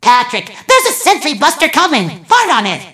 mvm_sentry_buster_alerts04.mp3